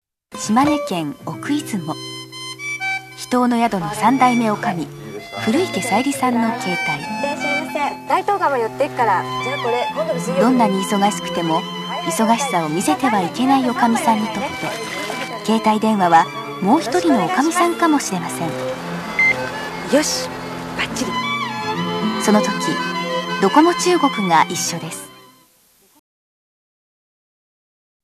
音声サンプル
明るく歯切れよい、温かみのある声質。音域が広いので、さまざまなご要望にマルチに対応します。
ナレーションは、元気なものから、包み込むような落ち着いたトーンまで。